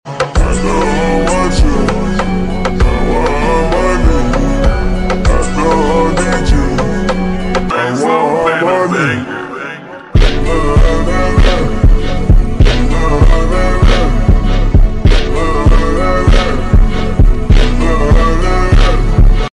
jersey club